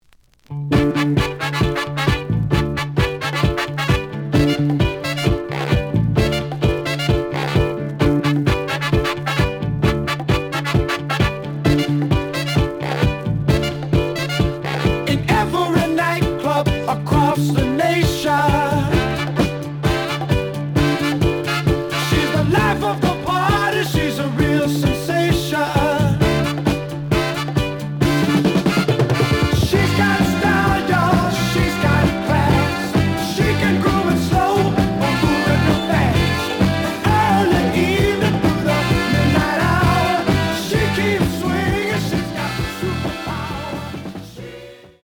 The audio sample is recorded from the actual item.
●Genre: Disco
Looks good, but slight noise on A side.)